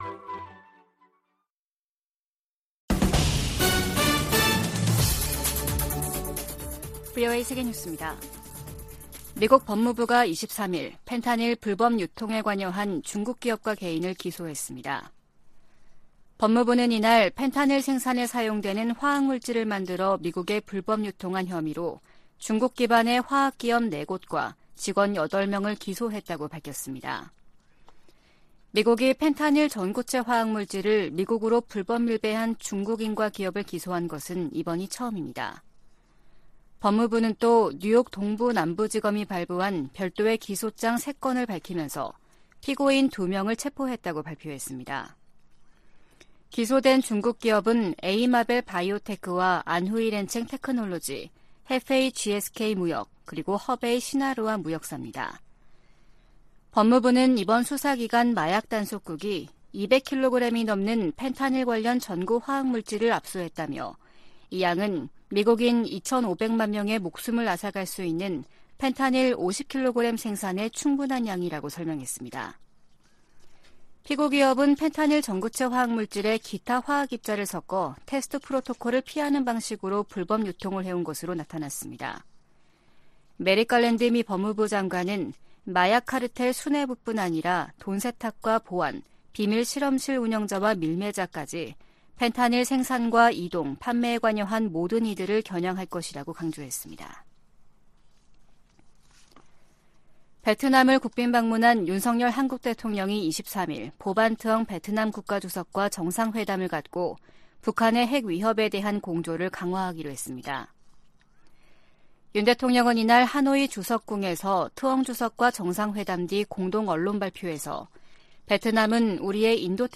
VOA 한국어 아침 뉴스 프로그램 '워싱턴 뉴스 광장' 2023년 6월 24일 방송입니다. 북한이 앞으로 몇 년동안 핵무기를 강압 외교에 활용할 가능성이 높다고 미국 정보당국이 전망했습니다. 조 바이든 미국 대통령과 나렌드라 모디 인도 총리가 북한의 미사일 발사를 규탄하고 한반도 비핵화 약속을 재확인했습니다. 한국 정부는 북한이 젊은 여성과 소녀를 내세워 체제 선전을 해 온 유튜브 채널들을 차단했습니다.